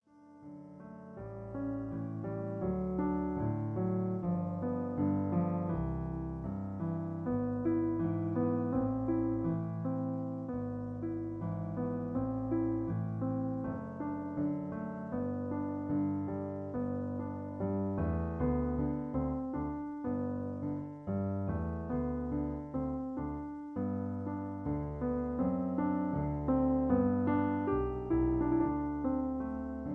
Original Key (G). Piano Accompaniment